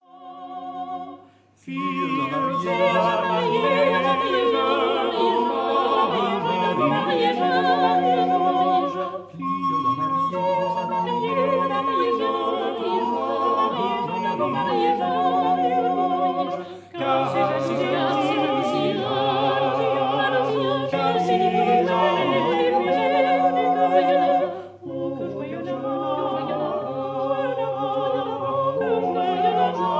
Recorded in Abbey Road studio 3, London,